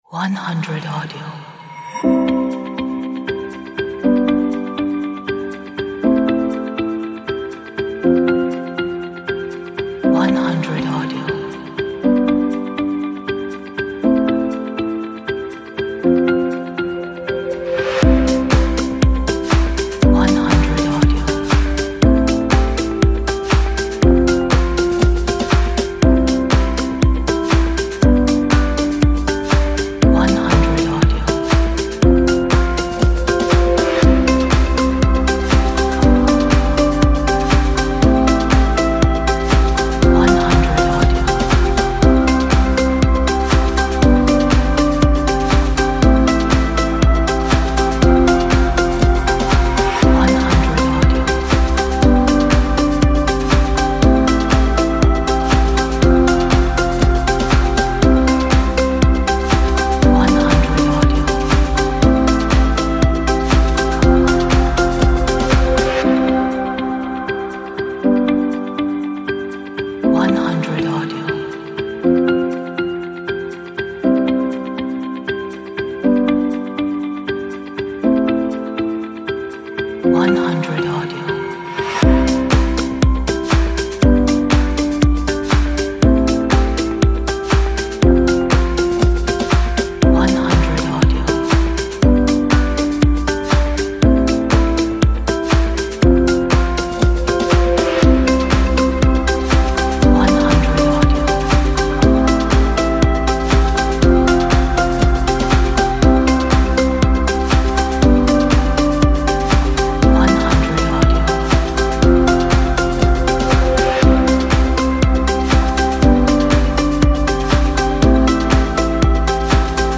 a nice pop upbeat inspirational track